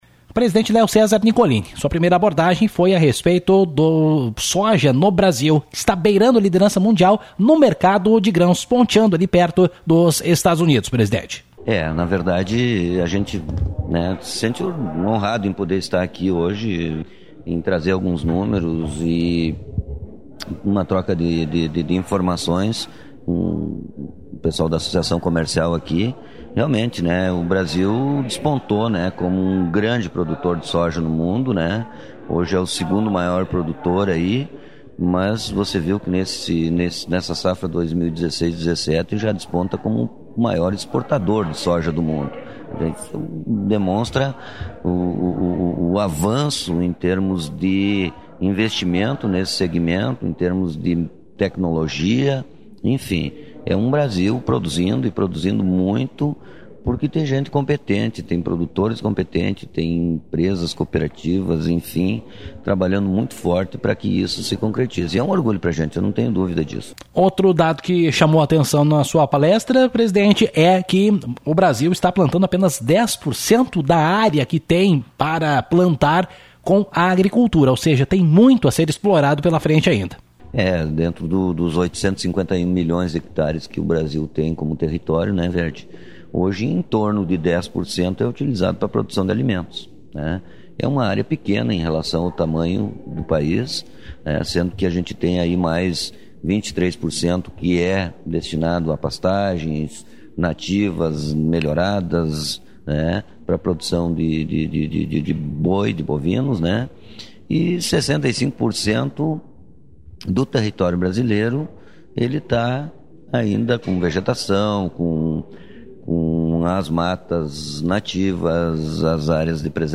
Na noite de terça-feira, 04, a Associação Comercial, Agropecuária, Industrial e de Serviços de Espumoso- ACISE promoveu sua reunião mensal.